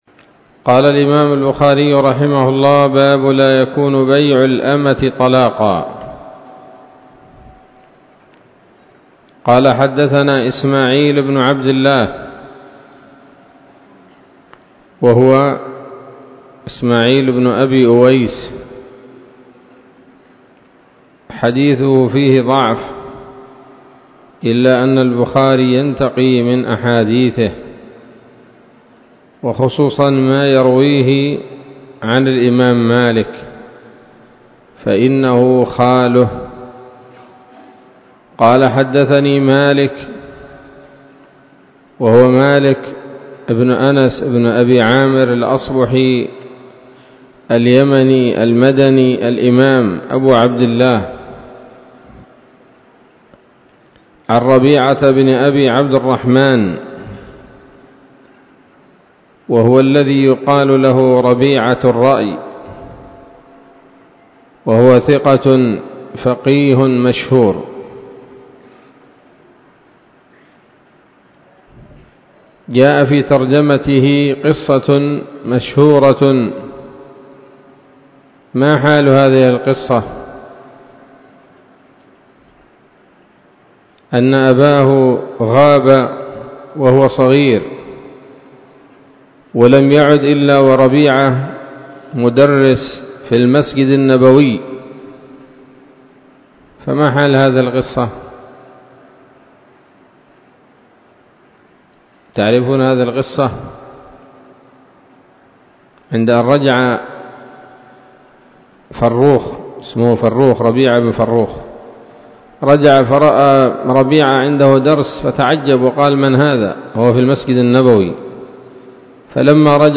الدرس الثاني عشر من كتاب الطلاق من صحيح الإمام البخاري